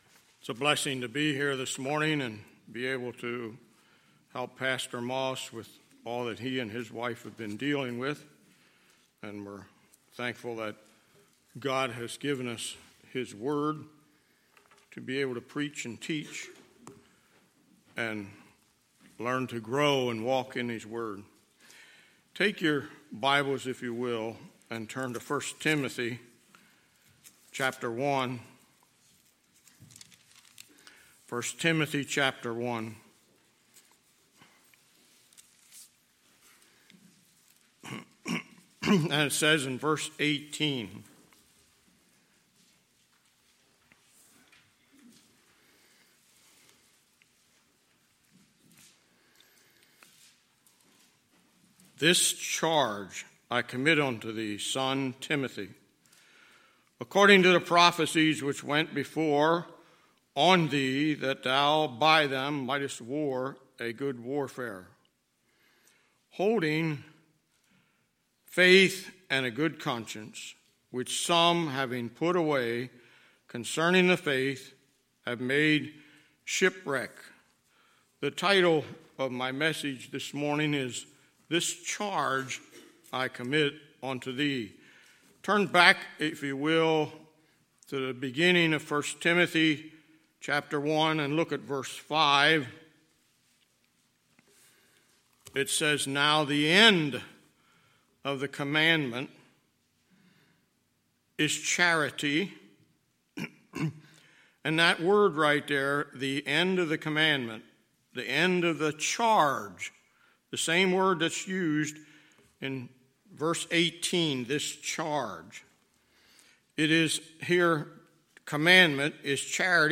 Sunday, September 29, 2019 – Sunday Morning Service